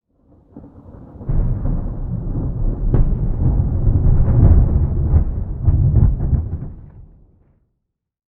thunderfar_6.ogg